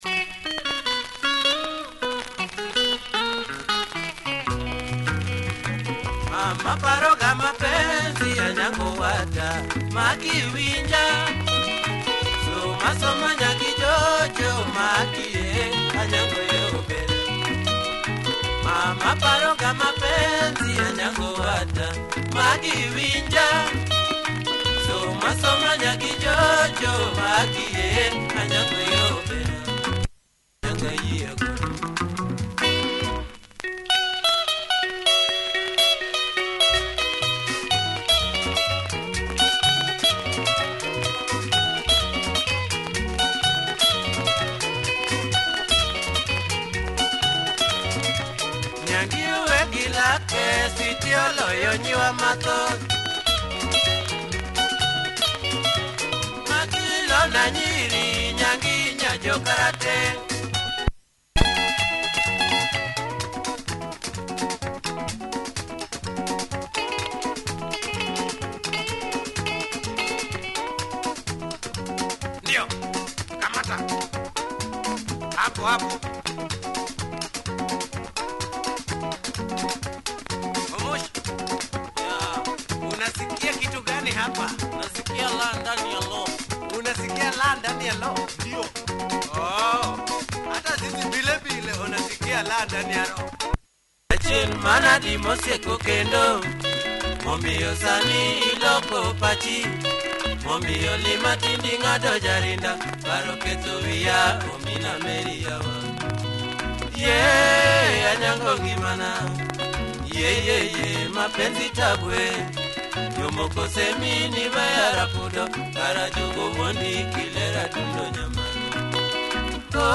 great mid-seventies luo benga!
Wear on the discs but plays through.